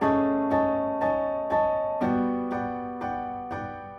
Index of /musicradar/gangster-sting-samples/120bpm Loops
GS_Piano_120-A1.wav